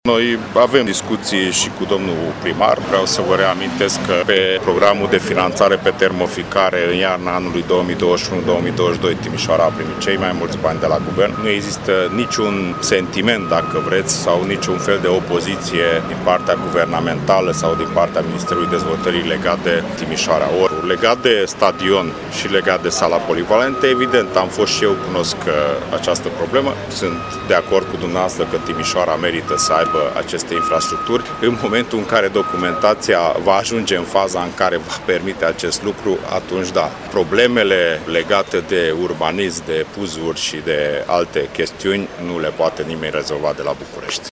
Ministrul Dezvoltării, Lucrărilor Publice și Administrației. a declarat pentru Radio Timișoara că Guvernul a ajutat municipalitatea de fiecare dată când a fost nevoie.